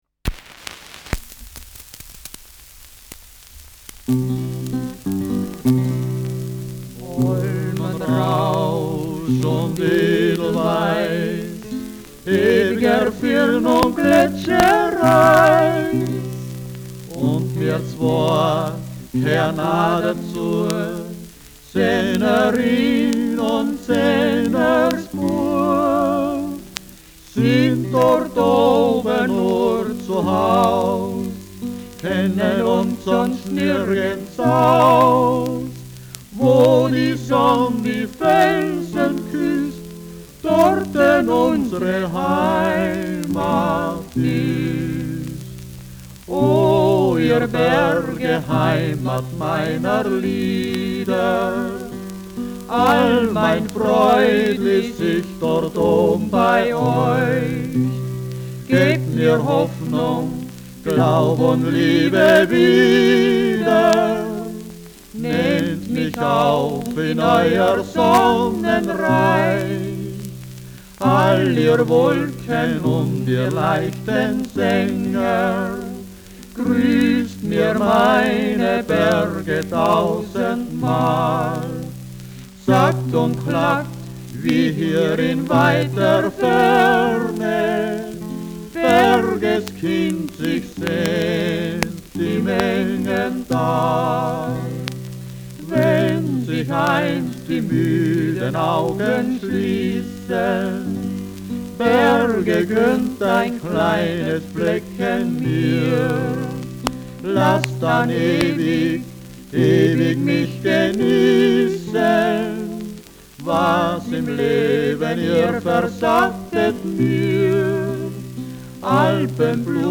Schellackplatte
Vereinzelt stärkeres Knacken
Folkloristisches Ensemble* FVS-00015